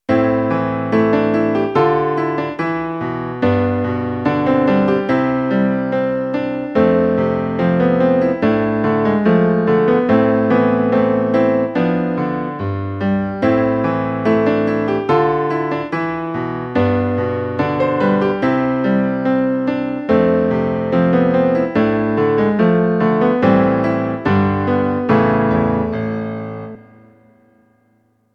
作例２（基本のコード進行）
同じメロディでも、伴奏が違うと全然イメージが変わってきますね。